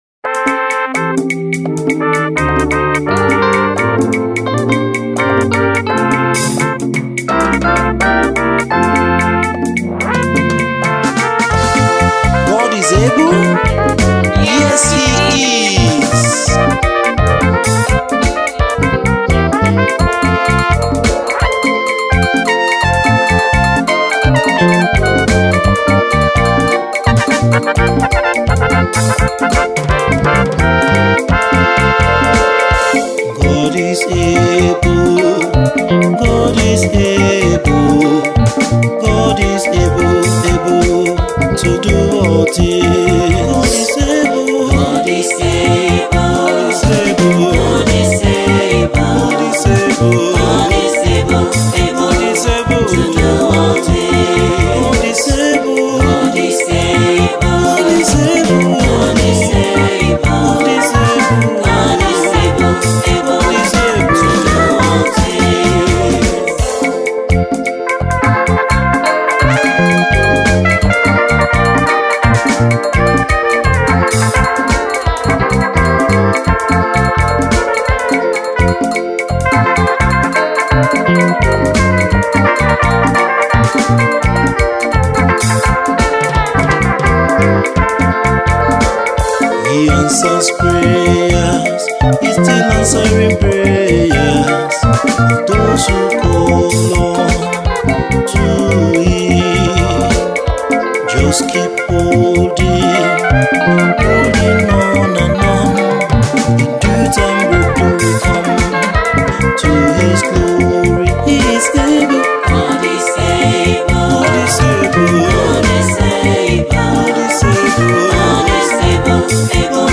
soul-lifting gospel track
With his calm yet passionate delivery